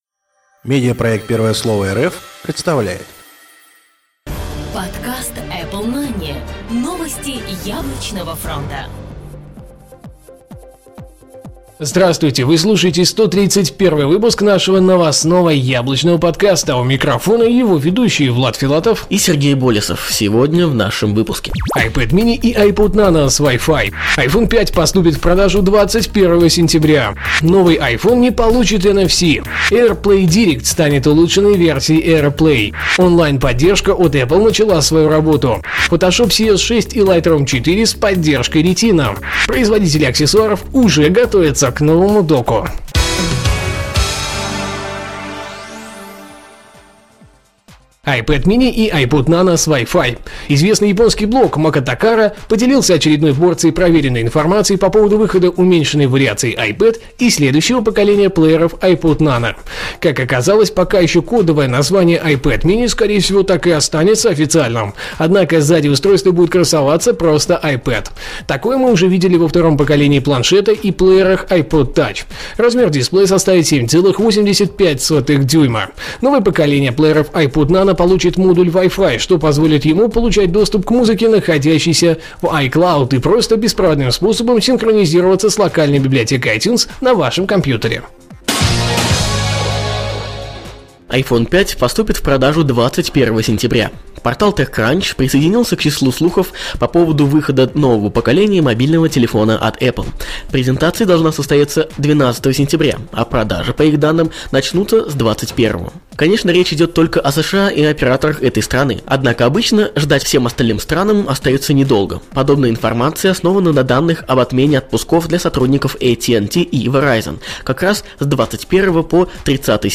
Жанр: новостной Apple-podcast